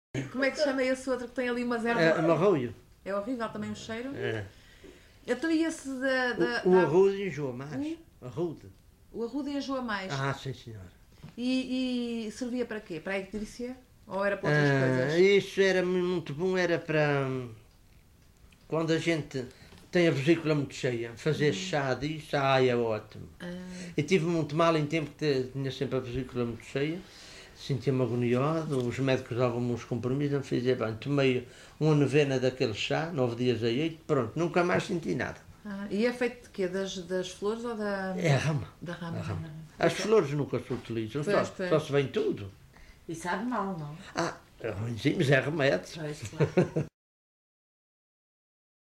LocalidadePedras de São Pedro (Vila do Porto, Ponta Delgada)